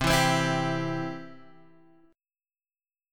C Chord
Listen to C strummed